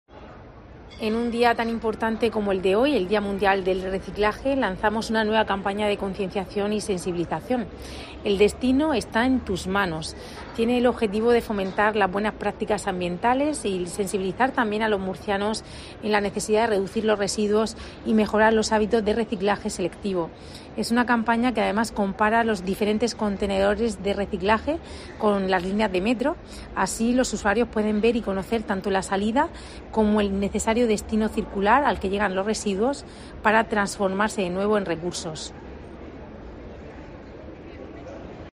Rebeca Pérez, vicealcaldesa de Murcia